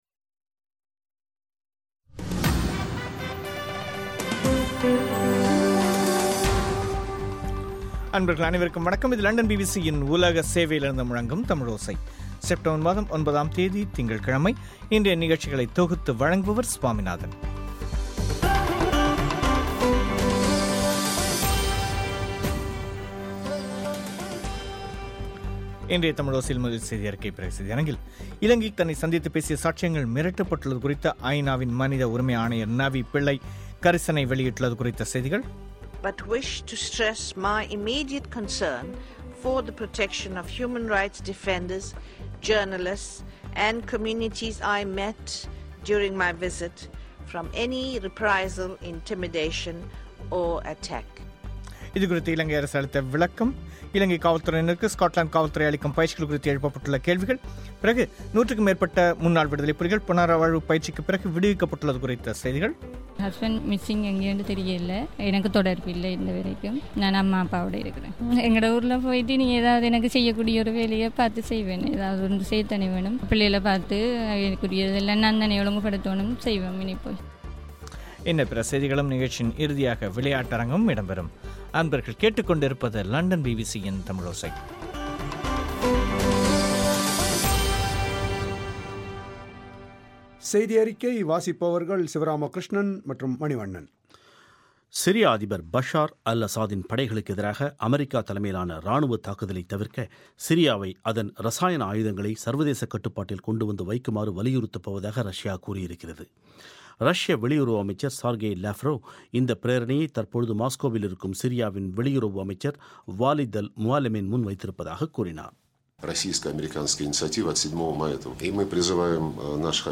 இன்றைய தமிழோசையில் முதலில் செய்தியறிக்கை, பிறகு செய்தியரங்கில்,